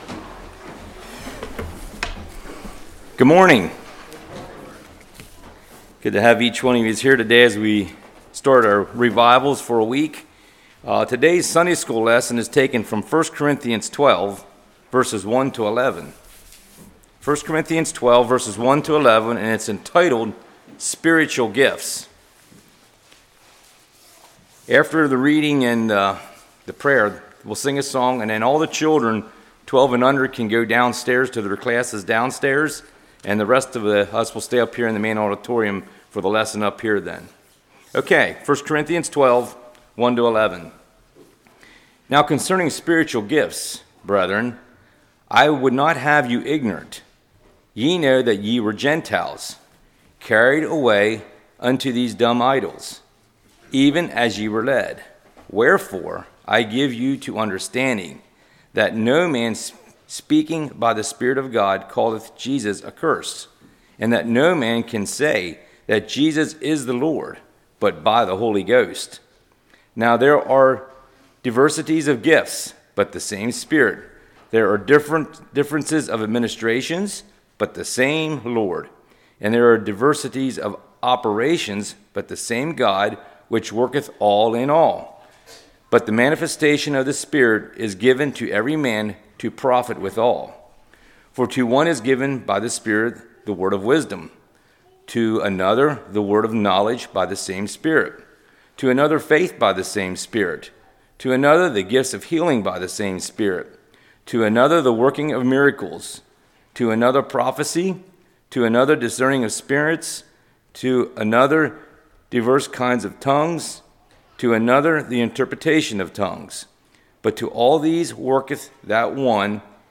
Series: Spring Revival 2023
1 Corinthians 12:1-11 Service Type: Sunday School Paul being a servant/willing to serve/lower himself.